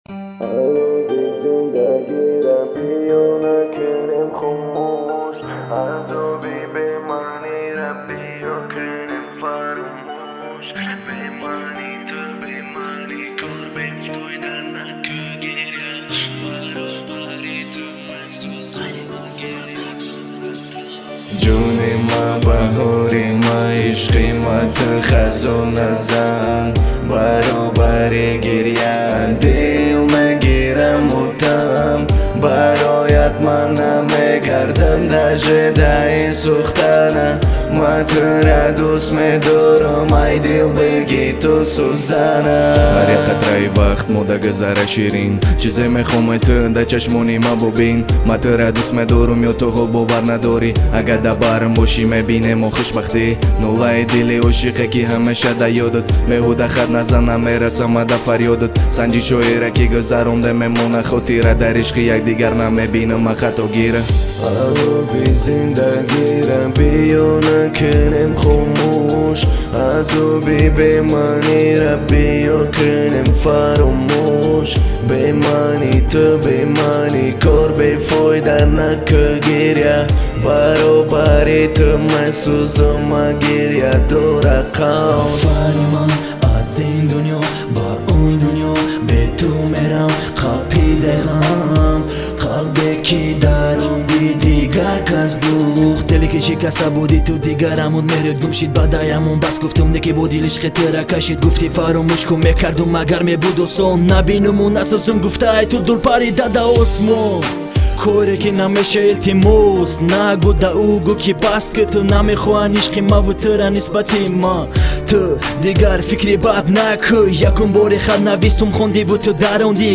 Таджикский рэп Автор